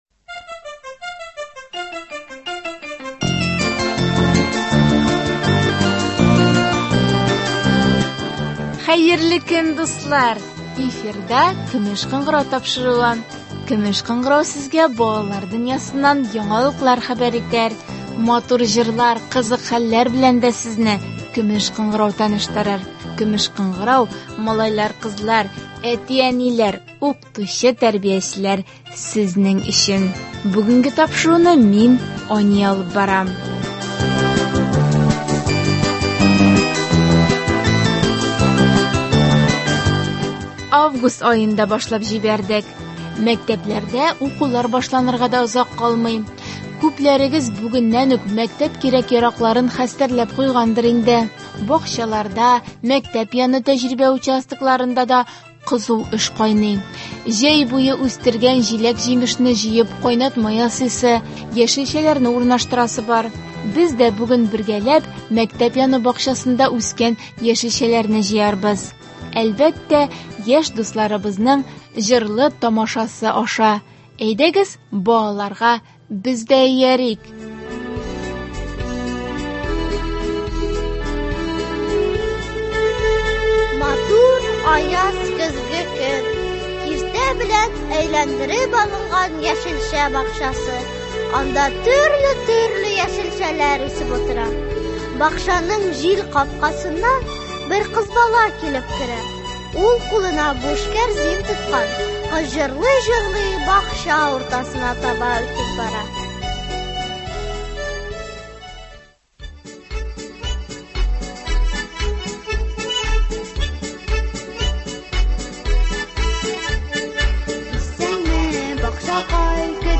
Без дә бүген бергәләп мәктәп яны бакчасында үскән яшелчәләрне җыярбыз. Әлбәттә , яшь дусларыбызның җырлы-тамашасы аша.